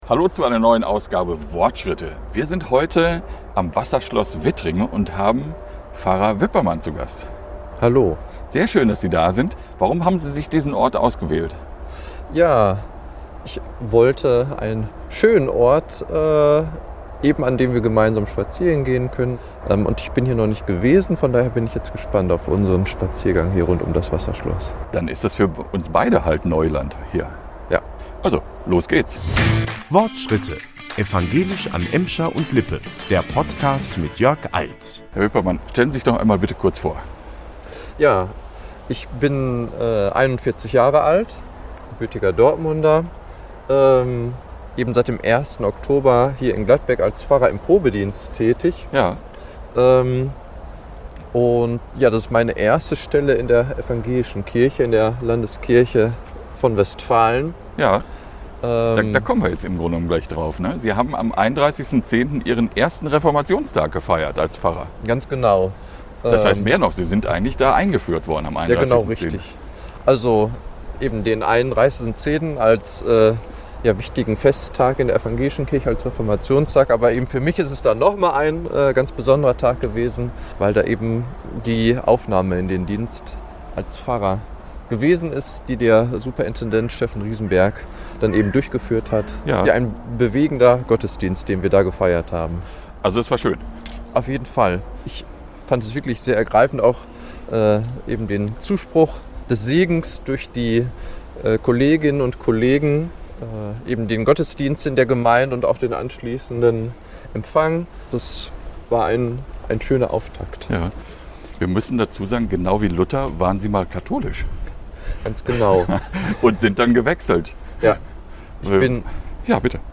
Das besondere Interview findet im Gehen statt, der Gast führt einen Schrittzähler und darf die Strecke selber auswählen.